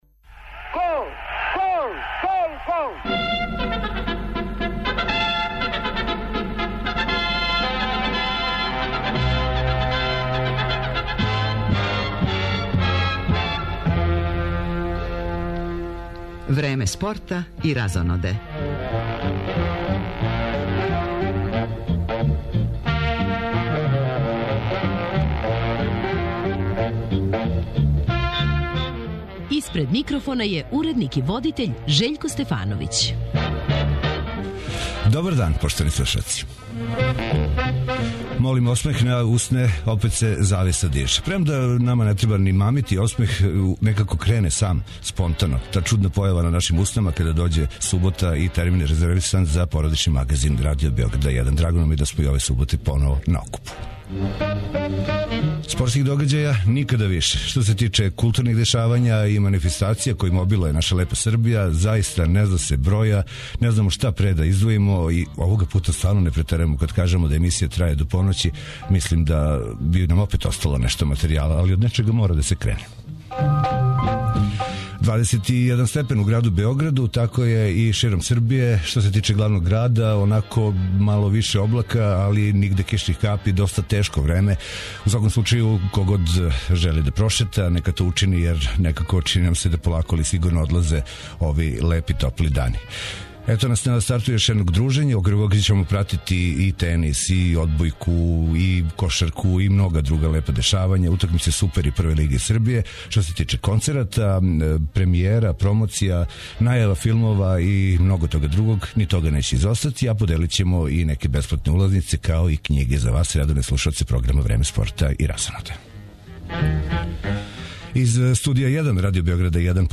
Породични магазин Радио Београда 1 комбиновано преноси дешавања на полуфиналном мечу Девис куп репрезентација Србије и Канаде у тенису и утакмицама Супер лиге Србије које су на програму овог поподнева.